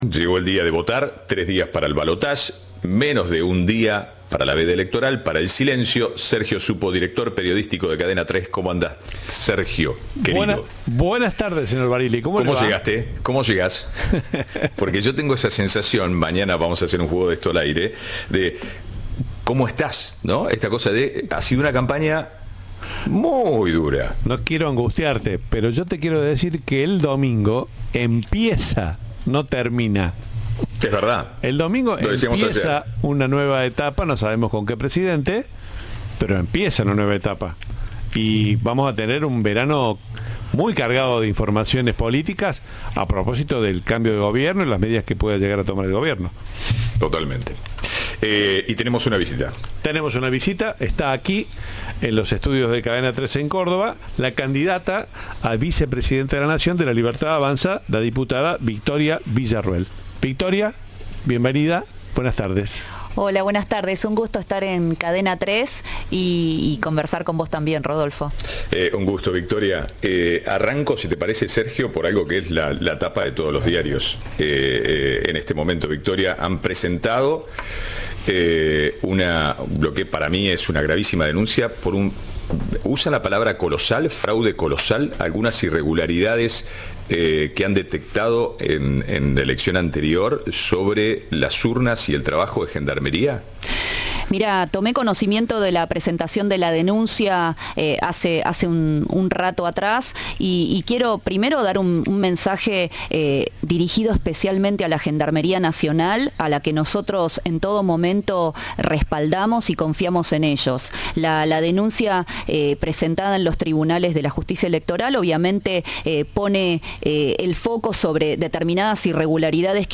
La candidata a vicepresidenta por La Libertad Avanza visitó Cadena 3 y dialogó con la mesa de Ahora País. Garantizó la fiscalización del domingo y defendió la educación pública.